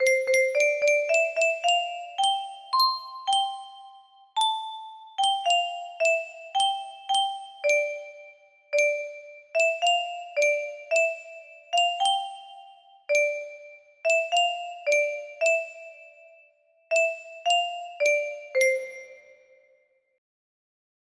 Julesang test music box melody